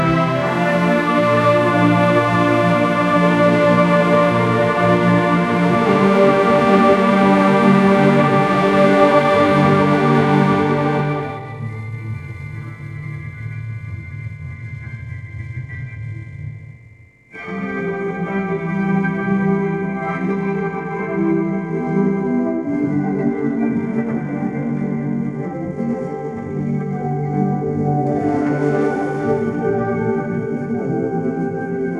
If someone wants to try generative AI music/looper maker I have a Colab that does that.